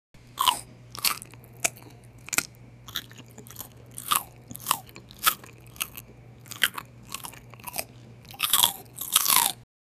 Annoying-sounds Sound Effects - Free AI Generator & Downloads